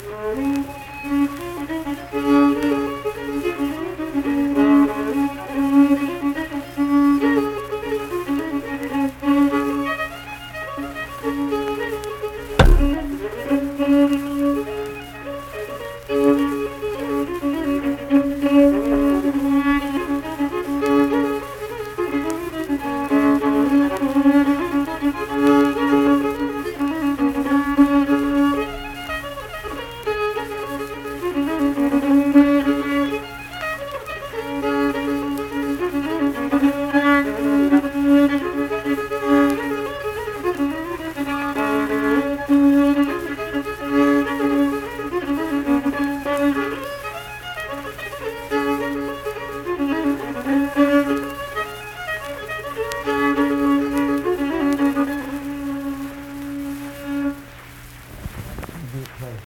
Unaccompanied fiddle music
Verse-refrain 3(2). Performed in Ziesing, Harrison County, WV.
Instrumental Music
Fiddle